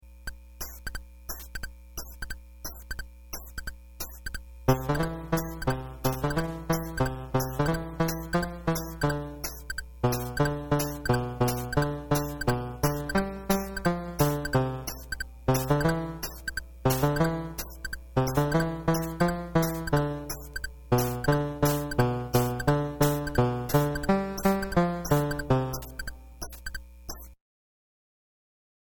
Casio VL-Tone
It has several instrument sounds and rhythms.
In this sample I used the Swing rhythm and I set the ADSR to sound like a banjo. The instrument has a line out which I connected to my iMac.
casiovl1.mp3